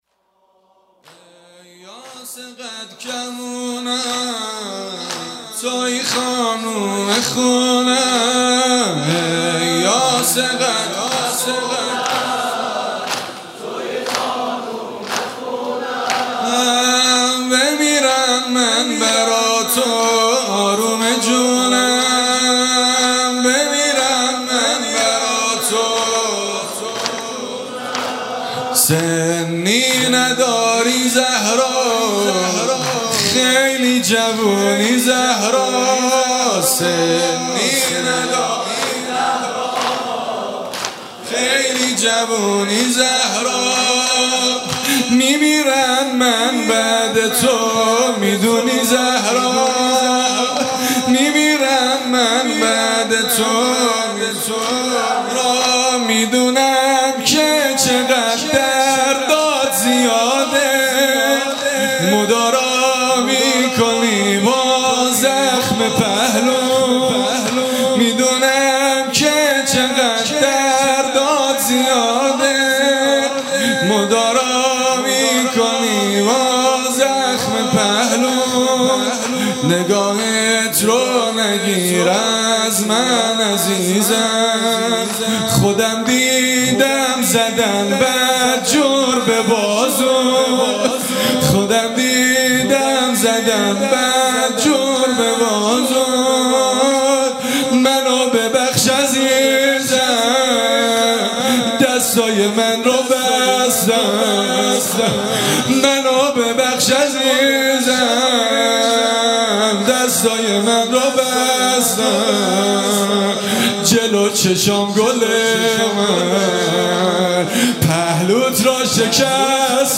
شب اول فاطمیه دوم ۱۴۰۲
music-icon زمینه: ای یاس قد کمونم، تویی خانم خونه‌ام